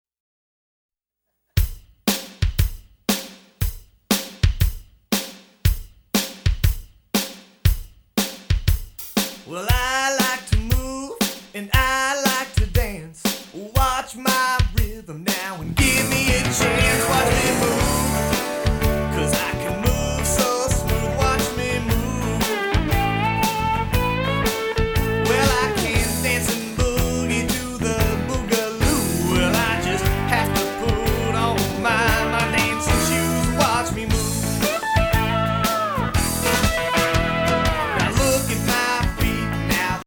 exciting and upbeat Rock, Pop and Funk